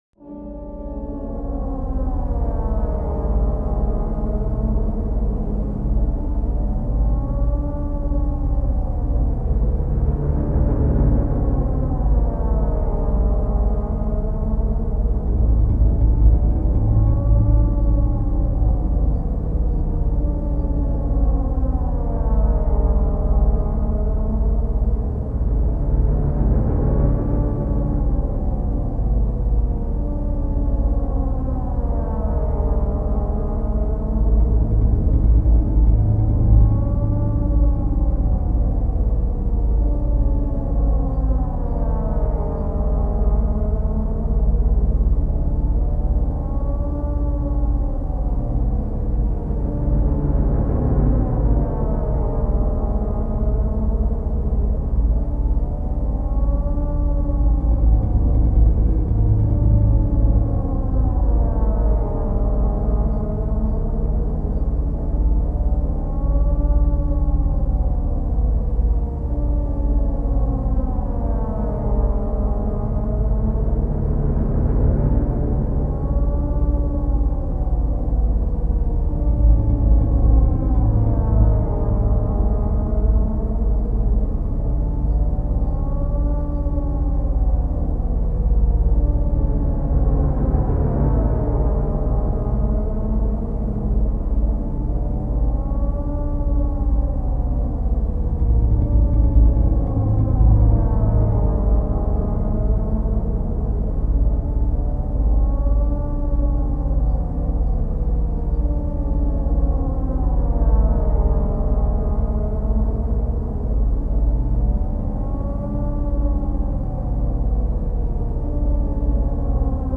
free horror ambience 2
ha-abomination.wav